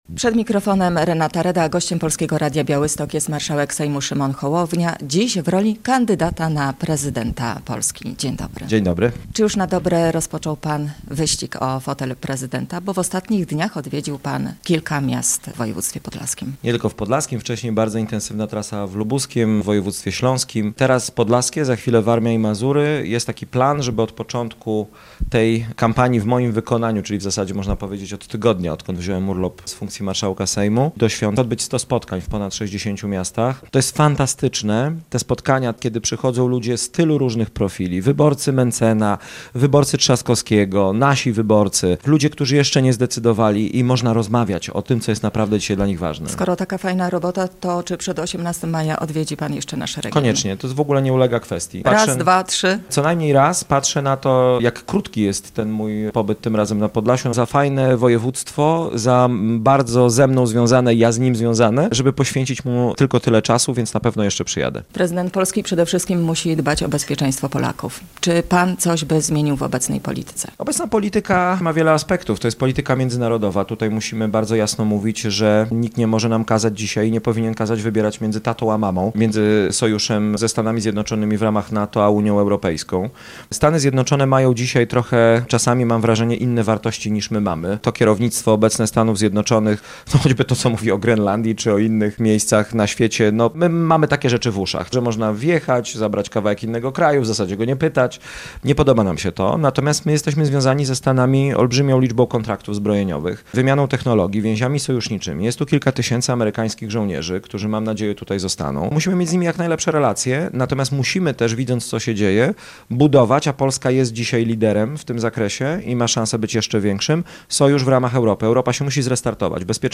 Radio Białystok | Gość | Szymon Hołownia [wideo] - marszałek Sejmu, kandydat na prezydenta RP